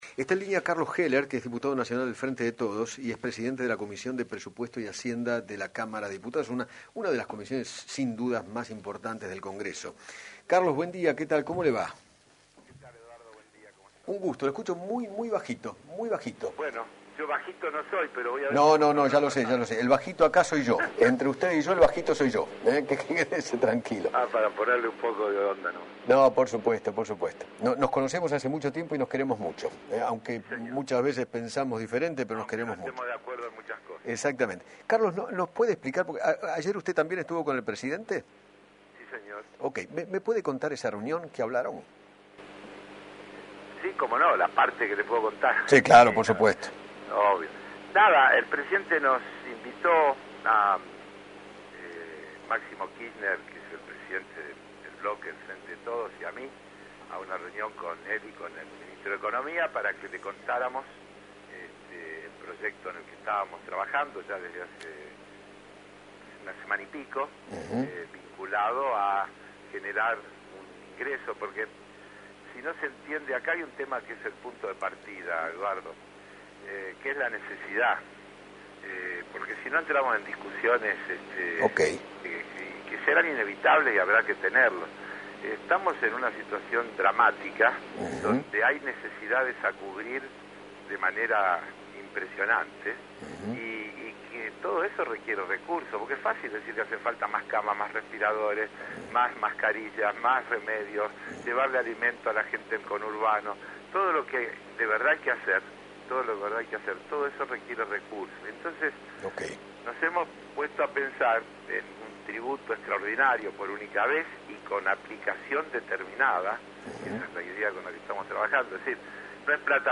José Cano, diputado Nacional de Juntos por el Cambio por Tucumán, dialogó con Eduardo Feinmann sobre el proyecto de Máximo Kirchner y Carlos Heller que le presentaron al Presidente y al Ministro de Economía para aplicar un impuesto especial a las grandes fortunas del país.